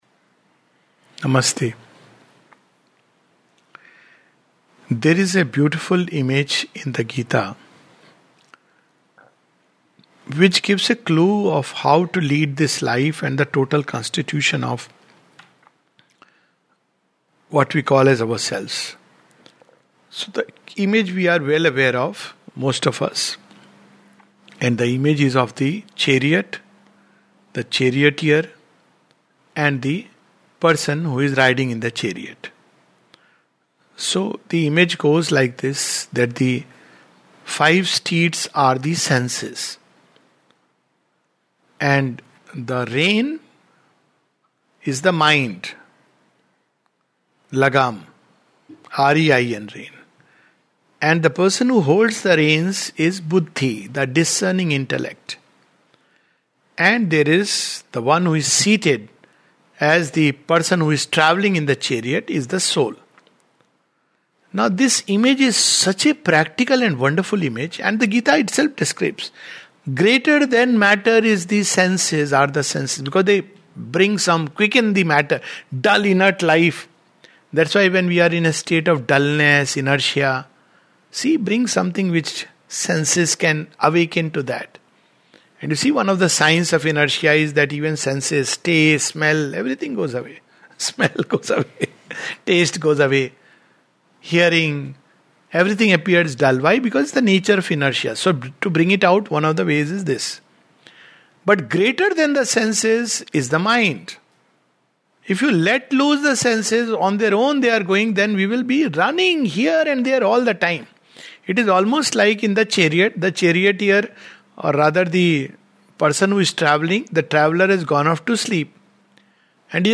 This talk is about the role of the discerning intellect in us.